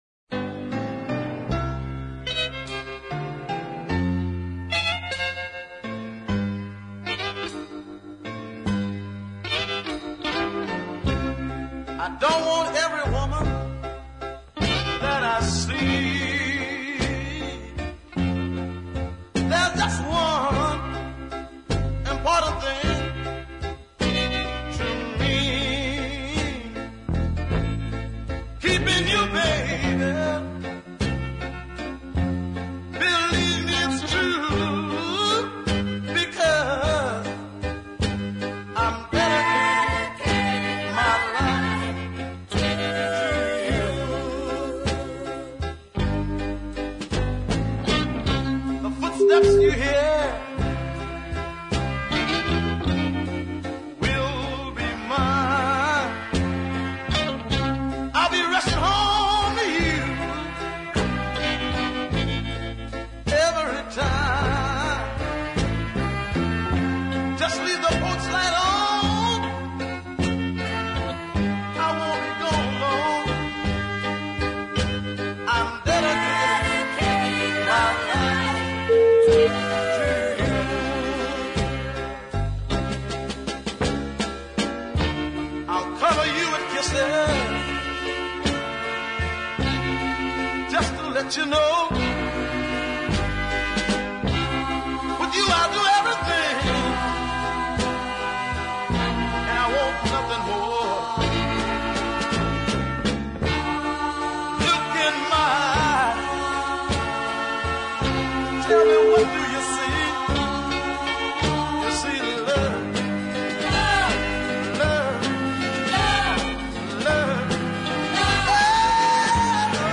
a deep soul winner
piano